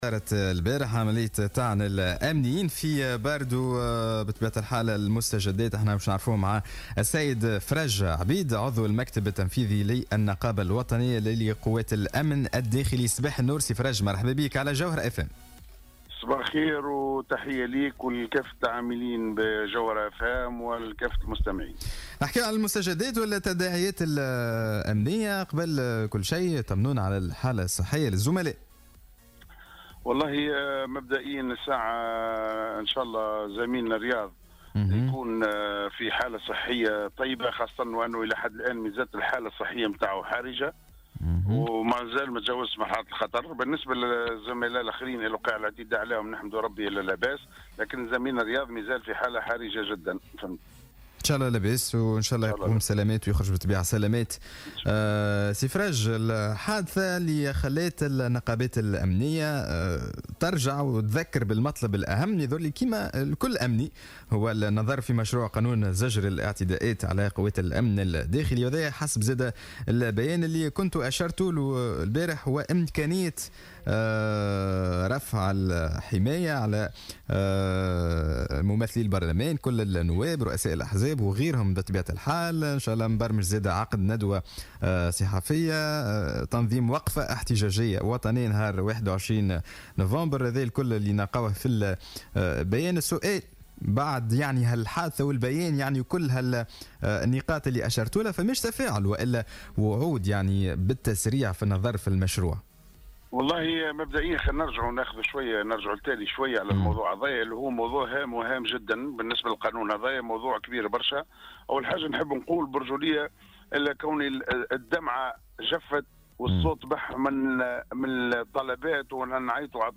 في مداخلة له على الجوهرة 'اف ام" صباح اليوم الخميس 2 نوفمبر 2017